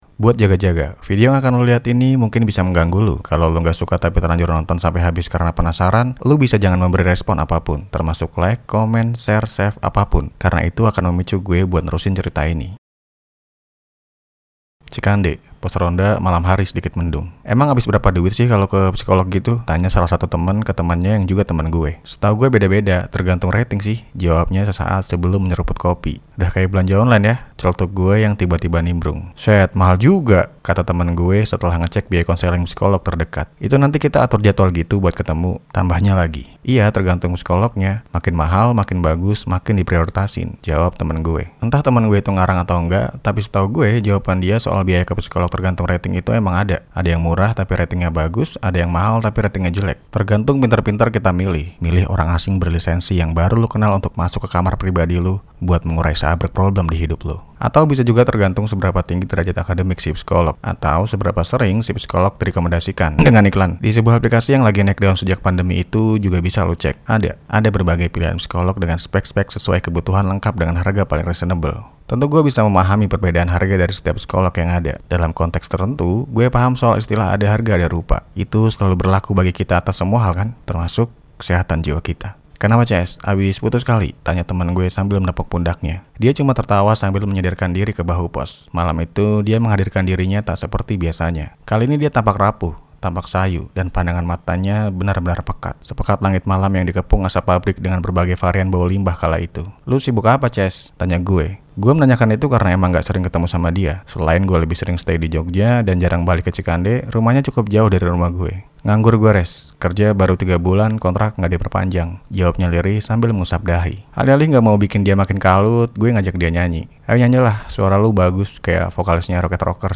Versi Sulih Suara